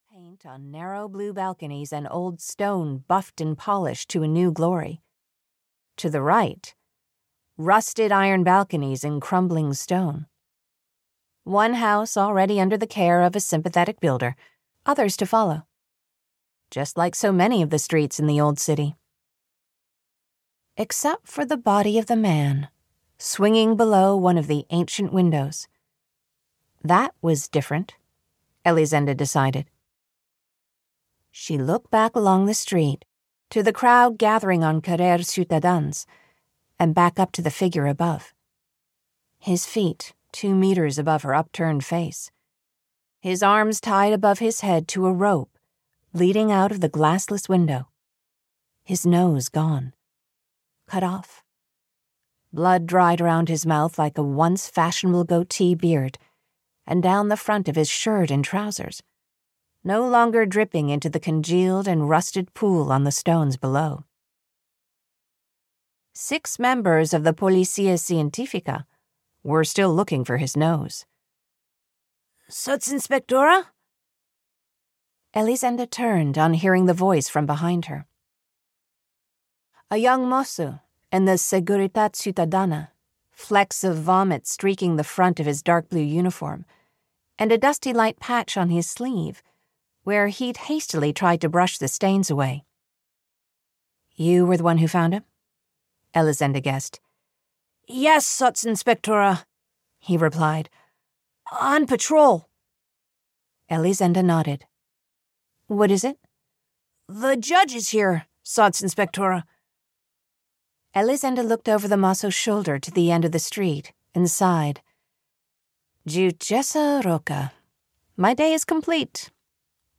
City of Good Death (EN) audiokniha
Ukázka z knihy